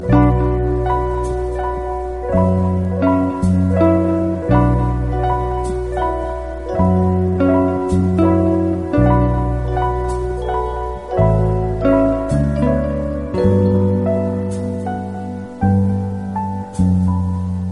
boucle de piano
guitariste américain de jazz